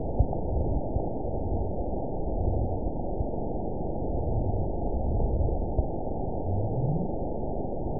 event 917334 date 03/28/23 time 04:33:03 GMT (2 years, 1 month ago) score 9.64 location TSS-AB01 detected by nrw target species NRW annotations +NRW Spectrogram: Frequency (kHz) vs. Time (s) audio not available .wav